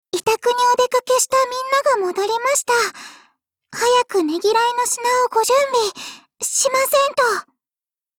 碧蓝航线:小光辉语音